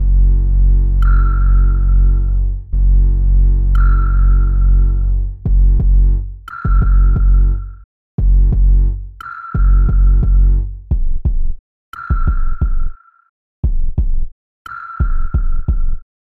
Tag: 88 bpm Chill Out Loops Percussion Loops 3.00 MB wav Key : Unknown